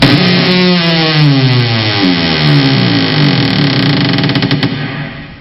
دانلود آهنگ در 7 از افکت صوتی اشیاء
جلوه های صوتی
برچسب: دانلود آهنگ های افکت صوتی اشیاء دانلود آلبوم صدای باز و بسته شدن درب از افکت صوتی اشیاء